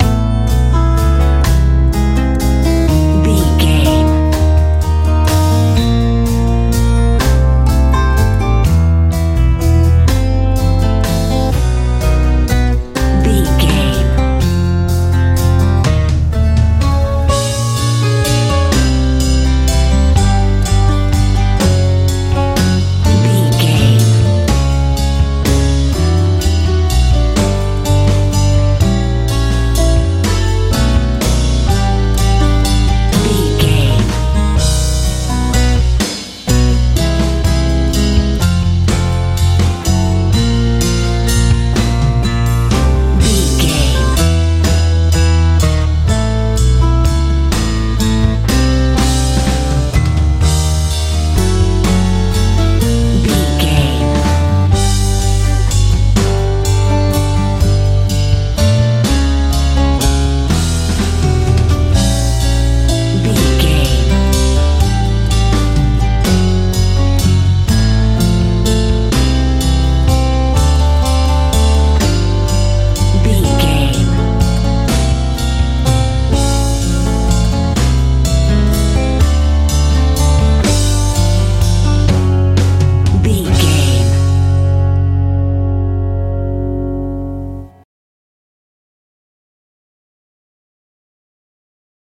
new wave feel
Ionian/Major
confident
acoustic guitar
bass guitar
drums
dreamy